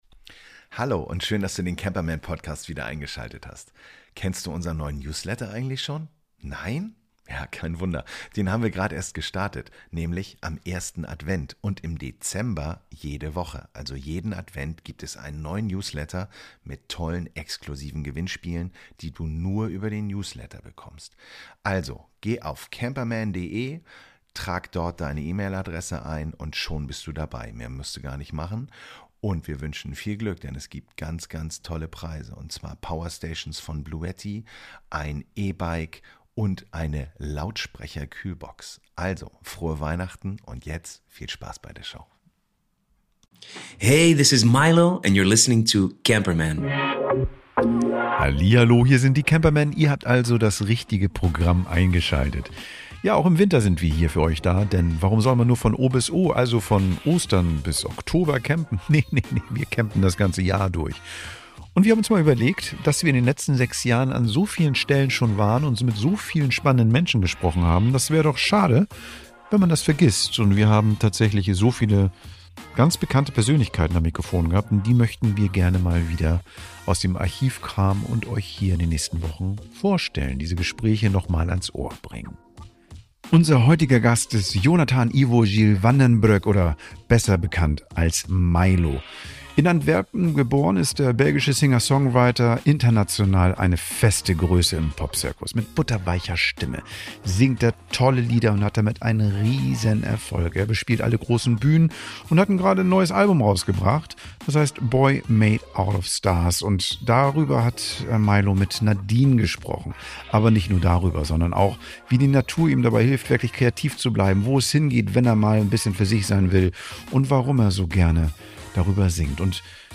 In dieser Folge unserer "VIP-Extras" veröffentlichen wir unser Interview mit dem Sänger Milow.